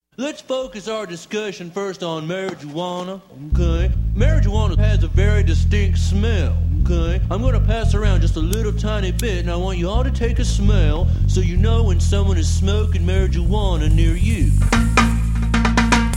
Features special guest intro